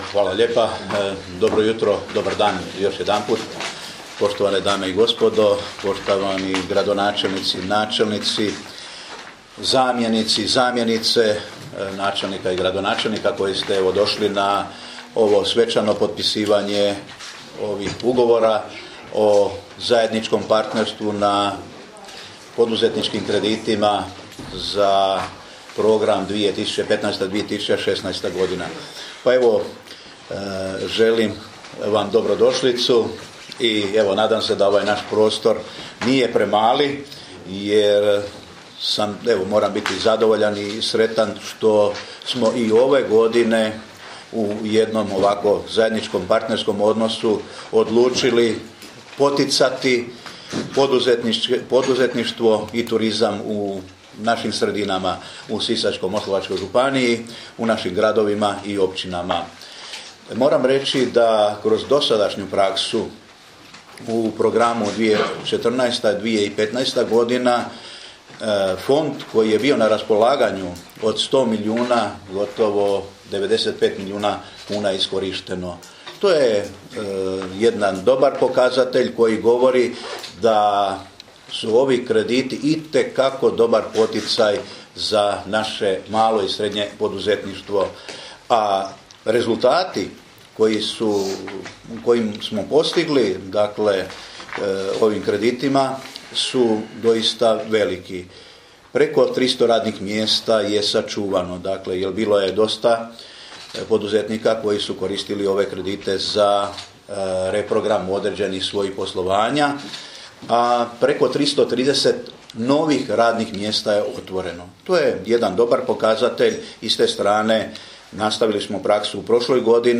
Izjavu, koju je u ovoj prigodi dao župan Ivo Žinić, možete poslušati ovdje: